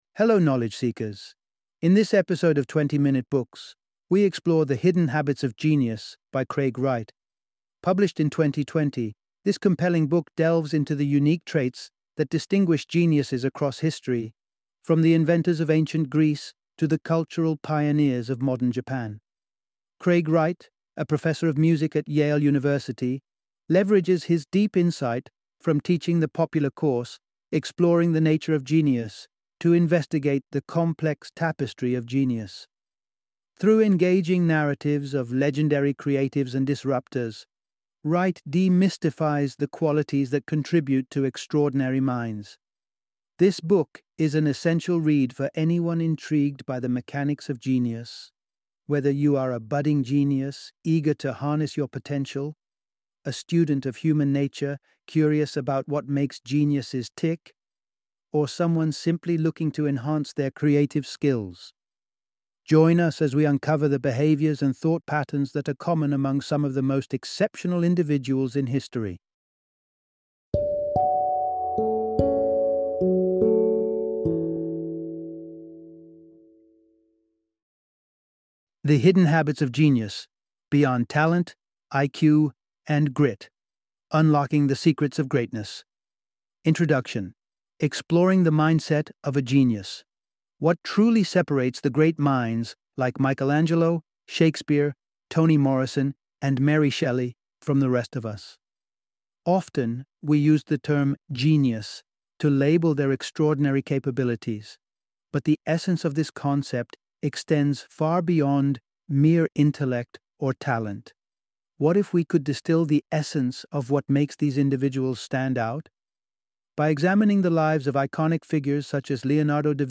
The Hidden Habits of Genius - Audiobook Summary